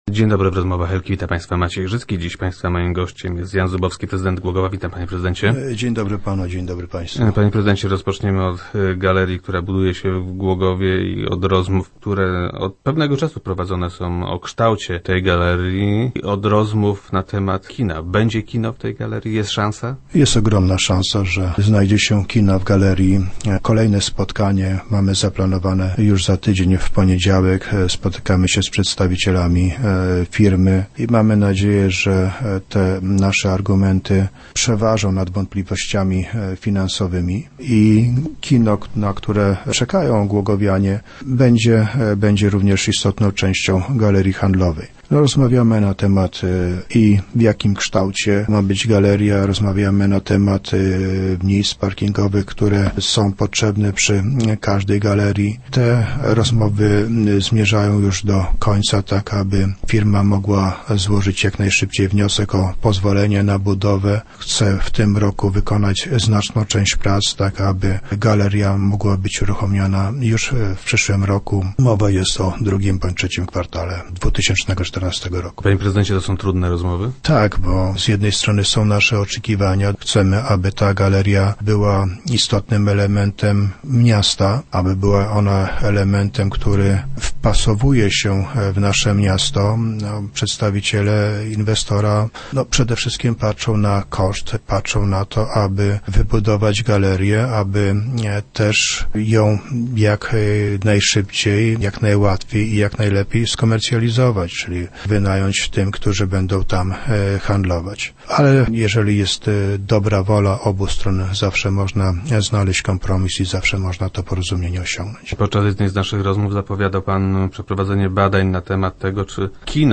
Zdaniem prezydenta Zubowskiego, który był gościem Rozmów Elki, tym razem ma być spokojniej.